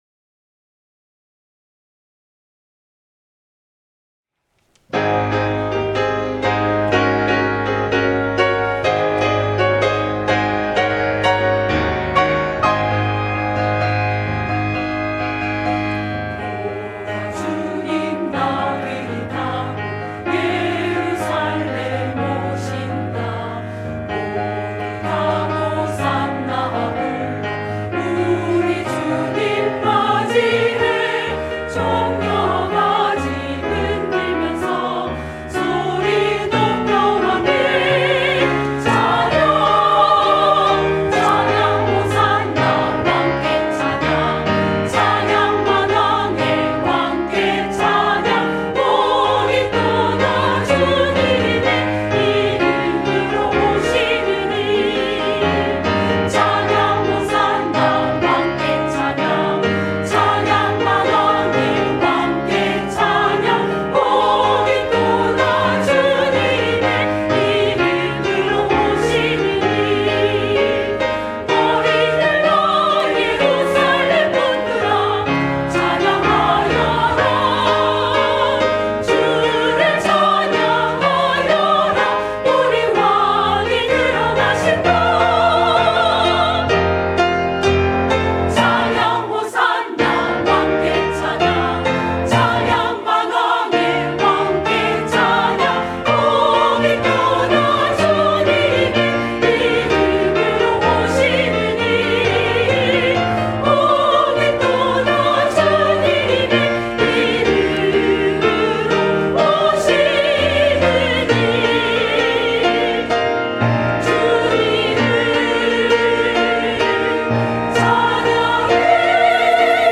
찬양 호산나